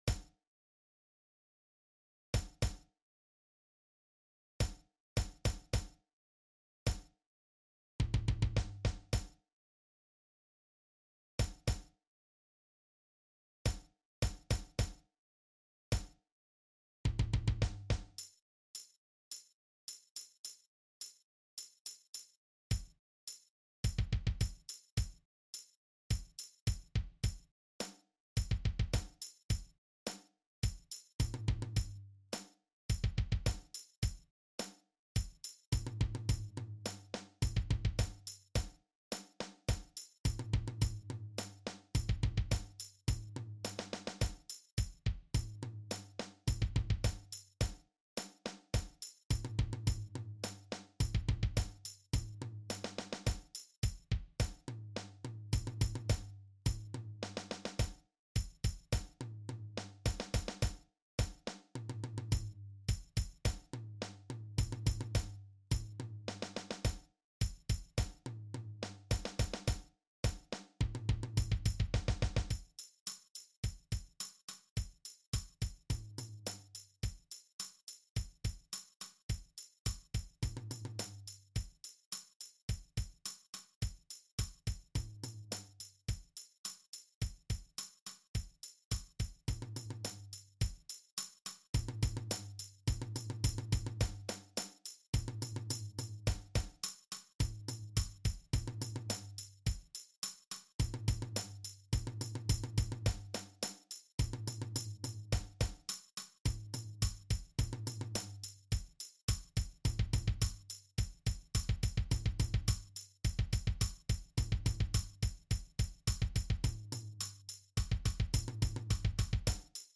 Jeugd Ensemble
Beatring Tom / Snare drum Bass drum